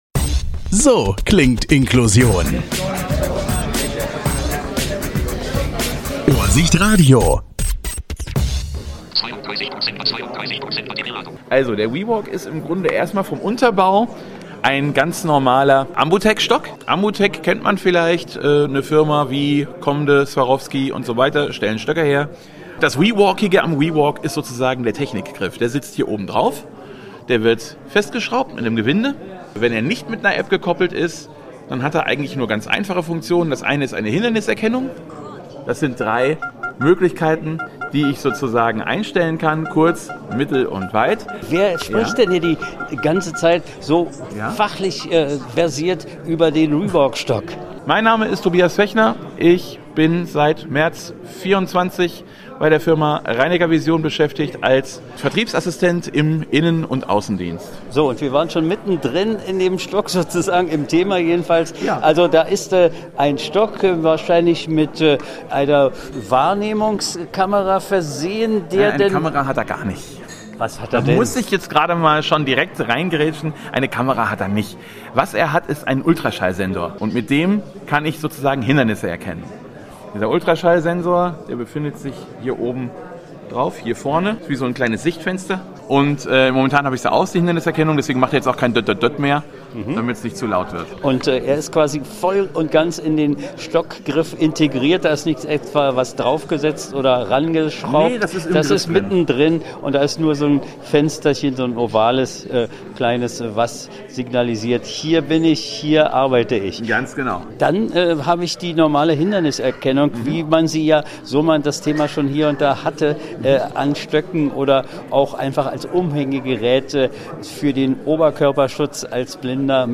Interview 28.11.2025